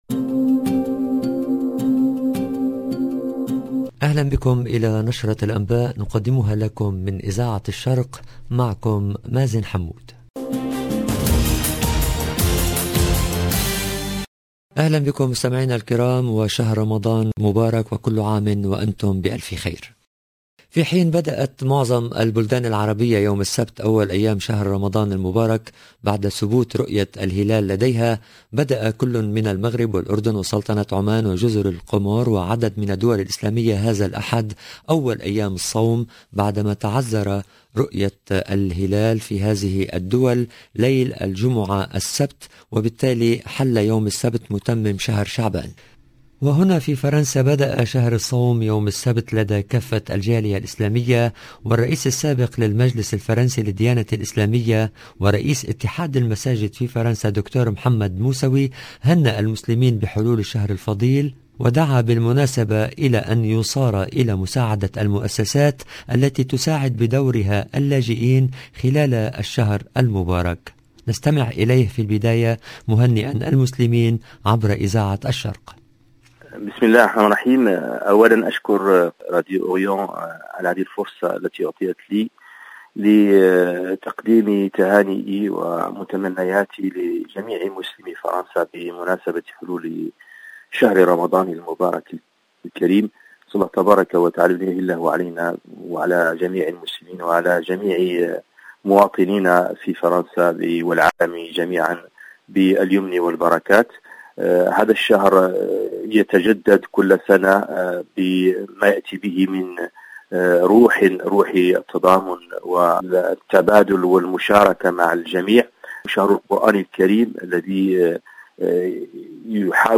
EDITION DU JOURNAL DU SOIR EN LANGUE ARABE DU 3/4/2022